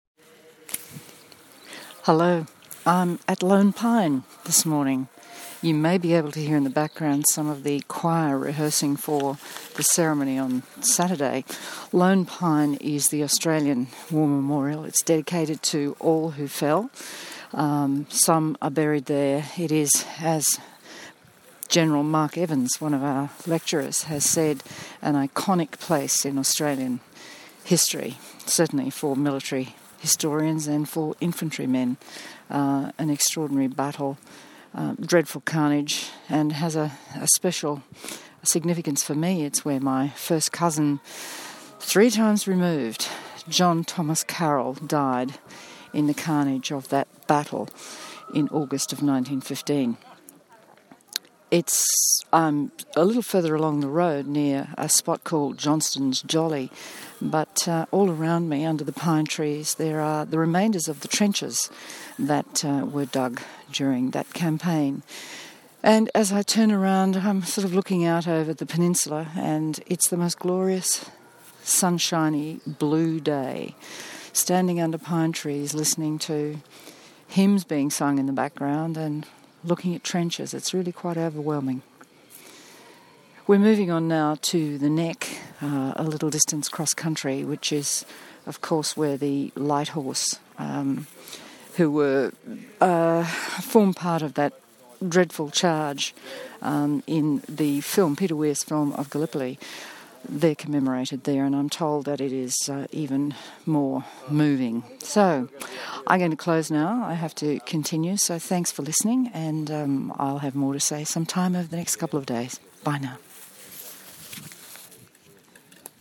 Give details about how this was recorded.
Lone Pine - Gallipoli Peninsula, Turkey